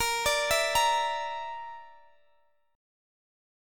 BbMb5 Chord
Listen to BbMb5 strummed